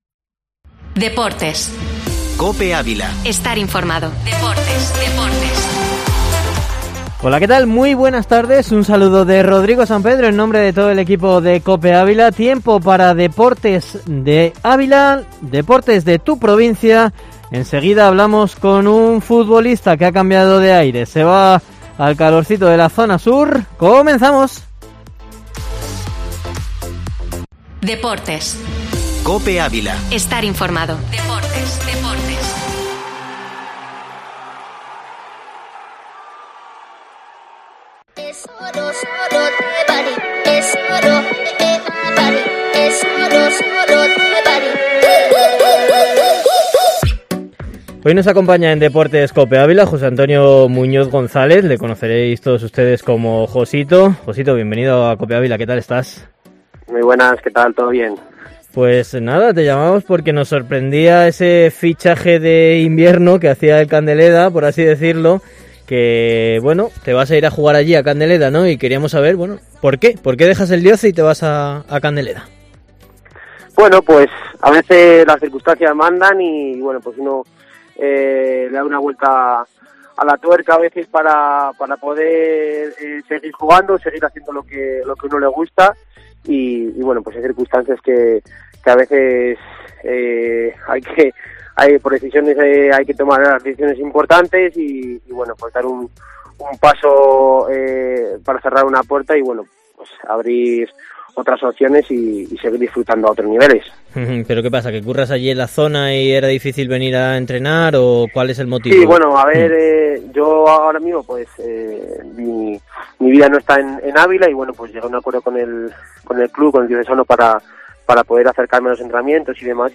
Deportes COPE Ávila Entrevista